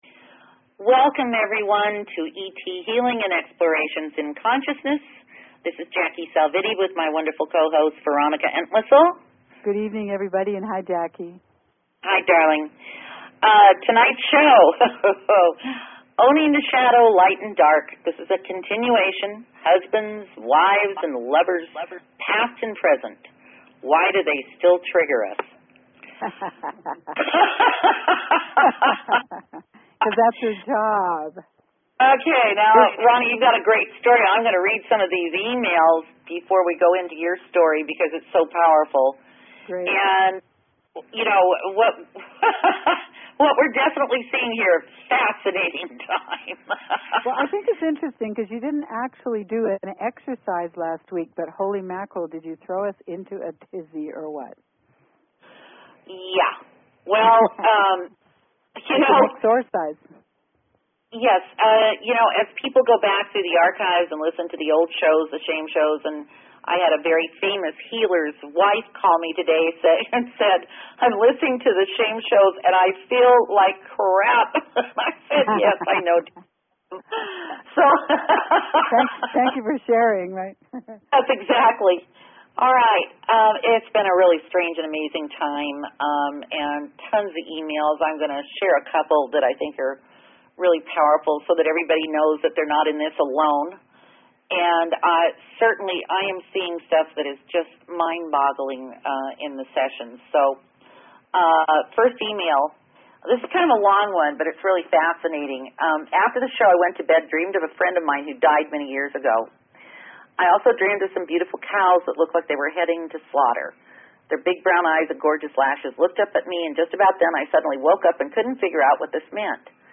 Talk Show Episode, Audio Podcast, ET_Healing and Courtesy of BBS Radio on , show guests , about , categorized as